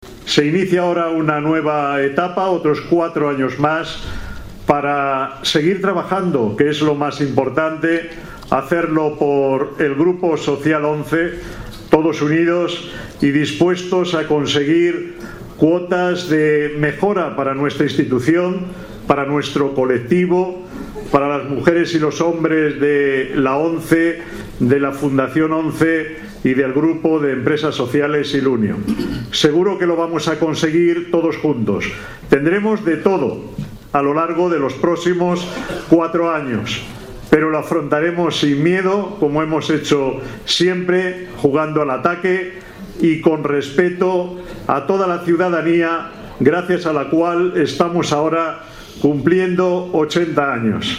expresaba así formato MP3 audio(0,97 MB) su valoración ante esta nueva etapa en la culminación de la noche electoral.